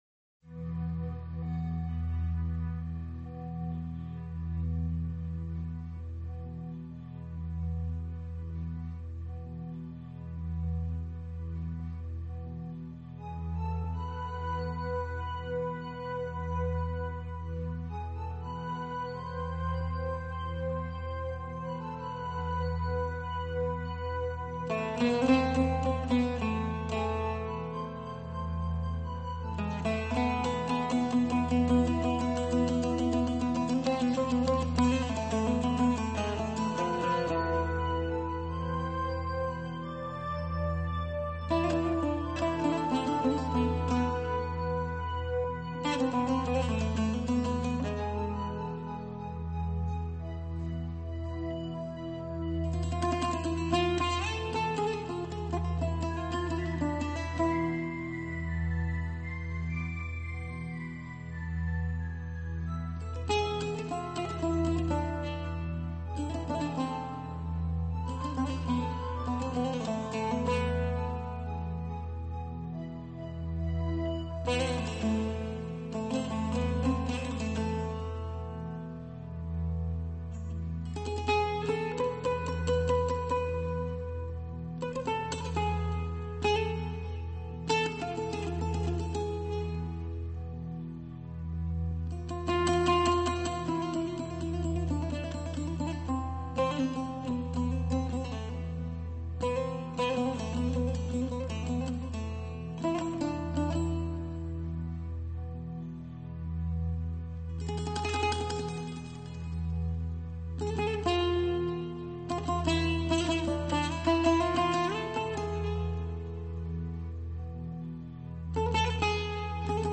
悲伤古调与现代抒情的动人摇摆 [漂流之歌]